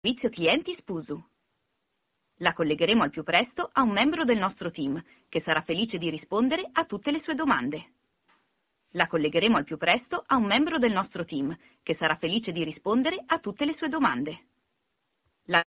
In basso la registrazione dell’IVR del servizio clienti del nuovo operatore Spusu.
servizioclienti-ivr-spusu-mondomobileweb.mp3